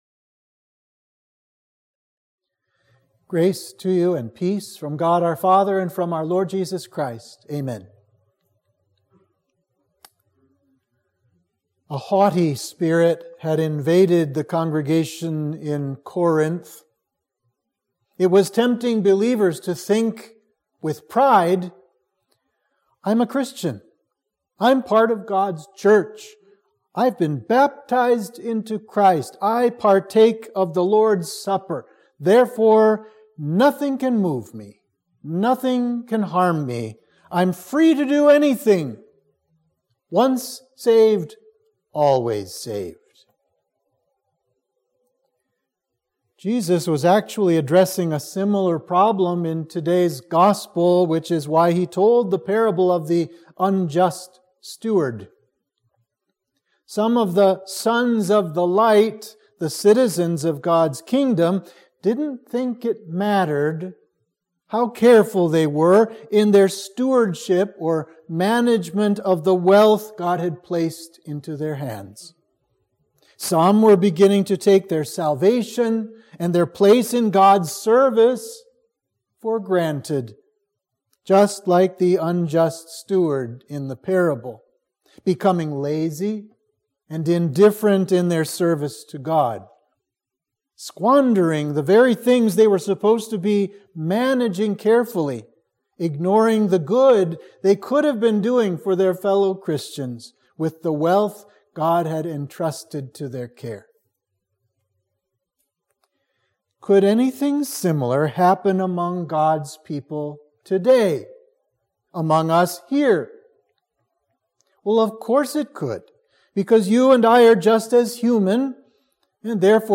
Sermon for Trinity 9